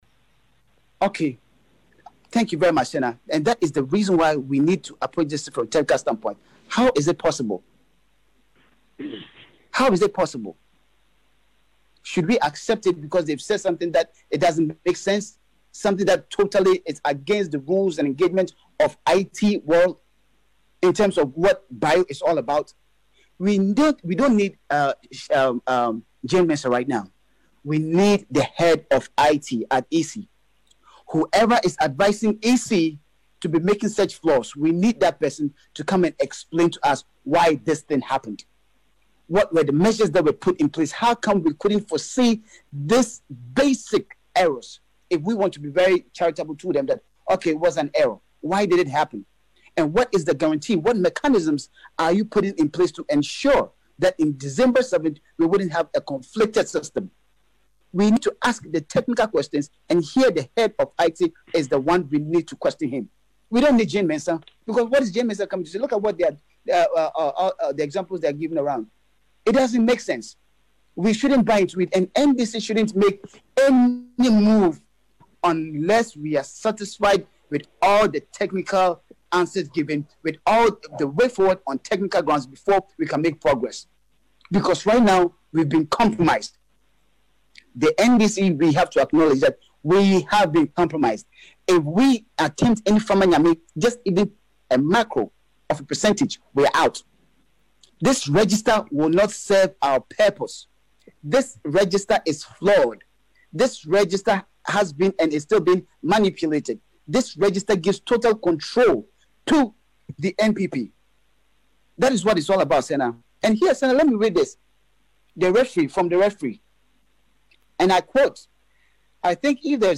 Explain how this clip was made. on the NDC Proforum Hour on Radio Gold Live on Sunday